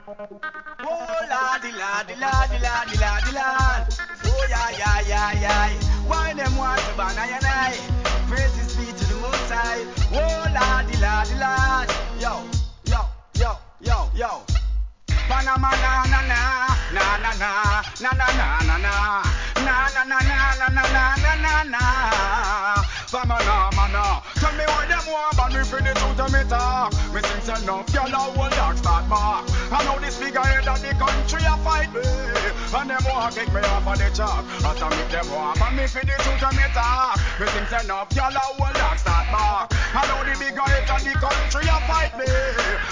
REGGAE
HIP HOP REMIX!!